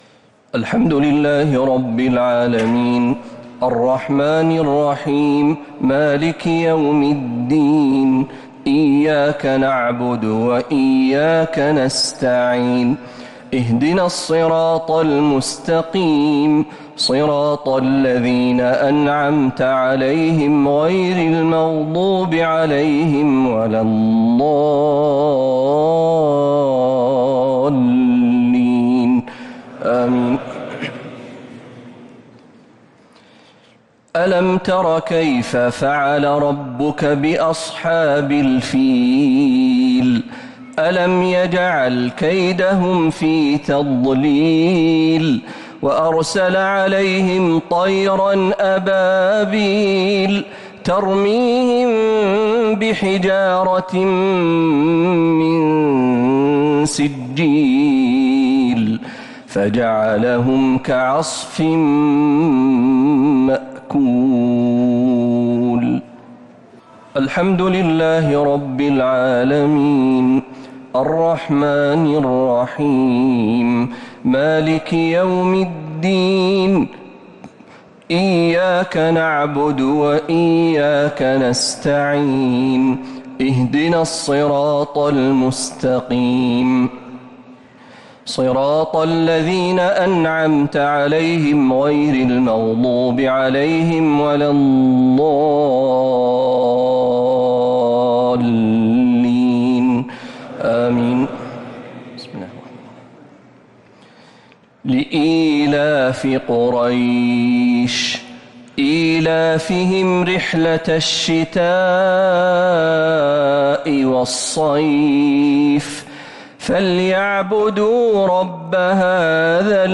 الشفع و الوتر ليلة 13 رمضان 1447هـ | Witr 13th night Ramadan 1447H > تراويح الحرم النبوي عام 1447 🕌 > التراويح - تلاوات الحرمين